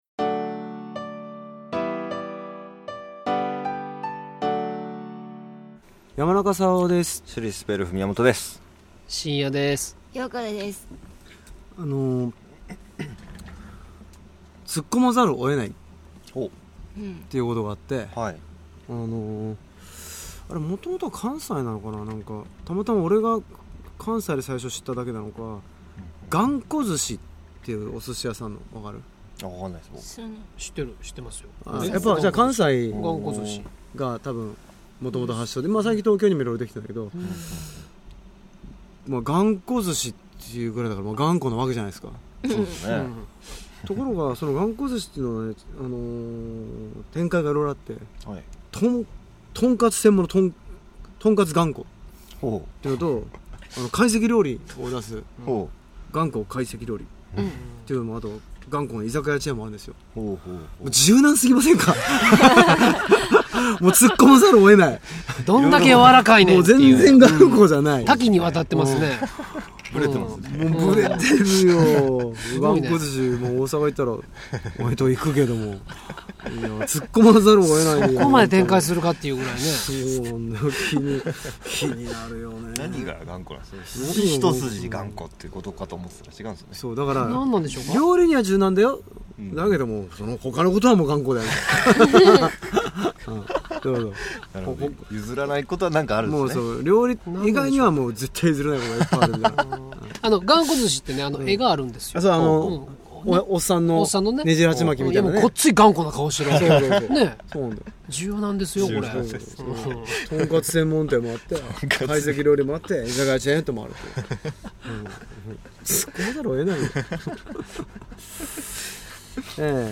Cast：山中さわお